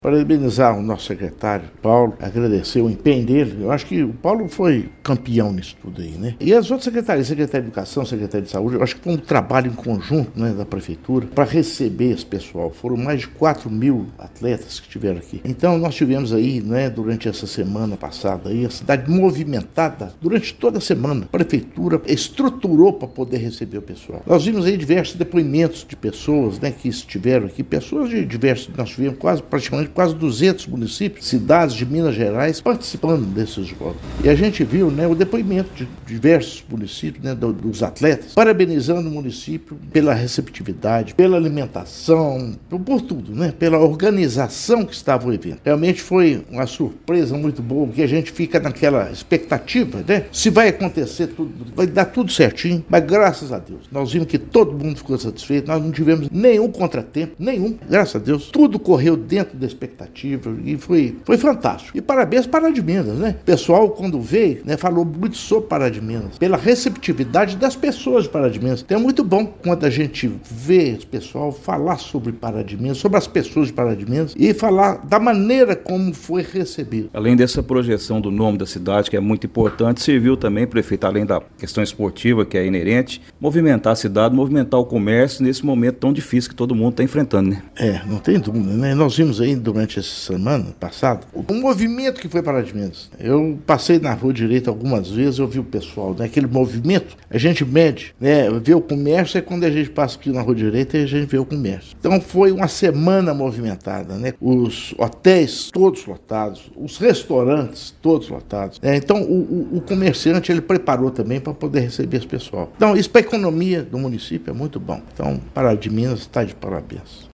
O prefeito Inácio Franco destacou o orgulho de ver Pará de Minas receber um evento dessa magnitude.